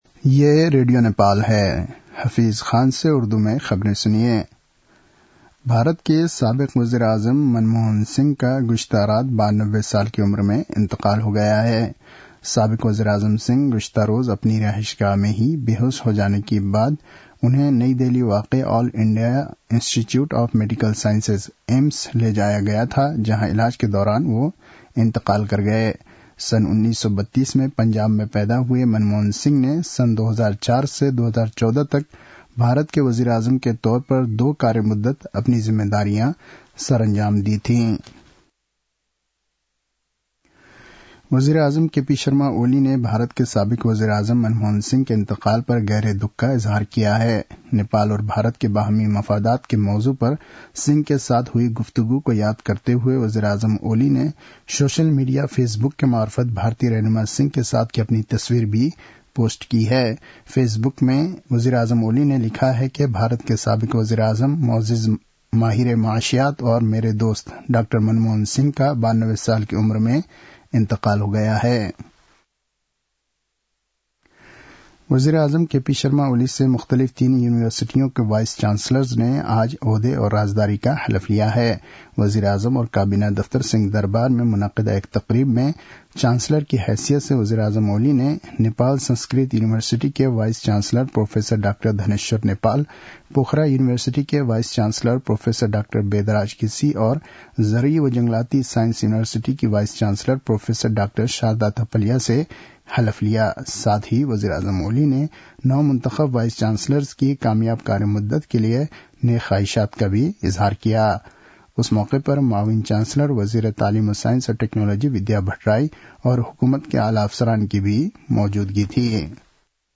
An online outlet of Nepal's national radio broadcaster
उर्दु भाषामा समाचार : १३ पुष , २०८१